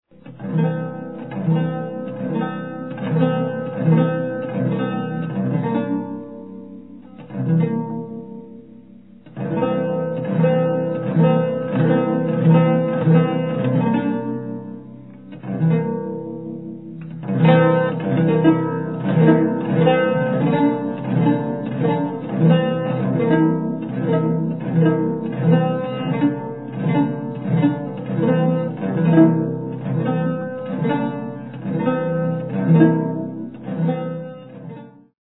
Oud Solo